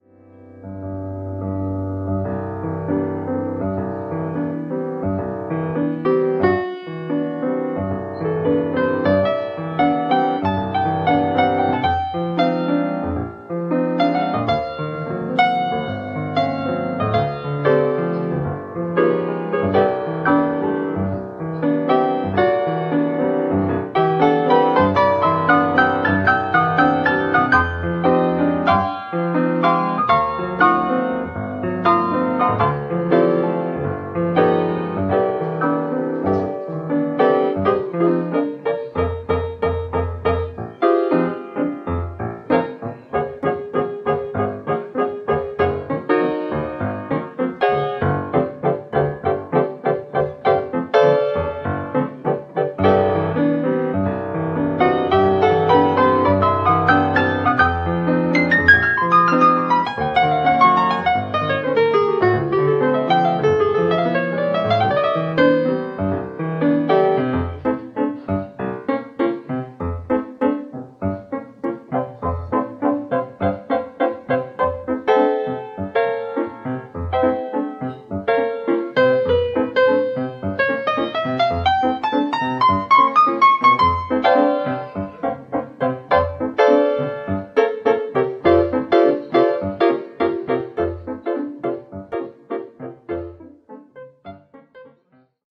solo piano.